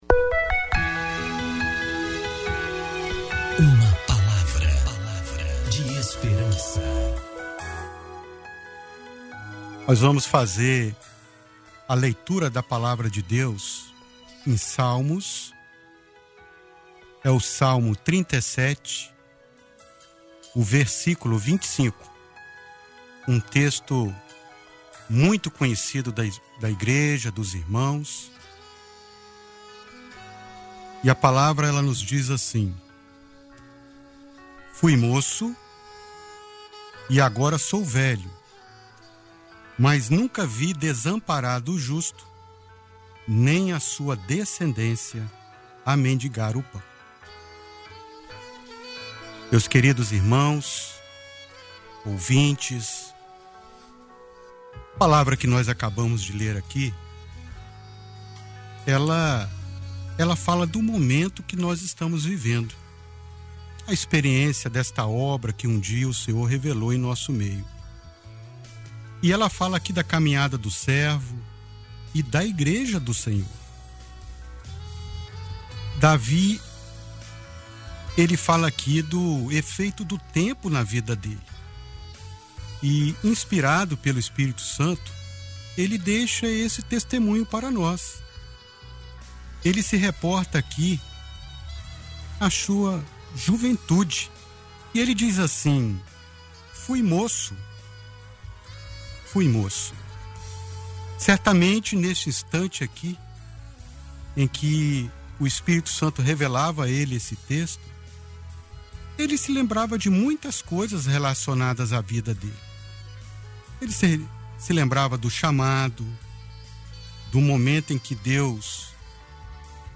Mensagem transmitida no dia 05 de agosto de 2020, dentro do programa Boa Tarde Maanaim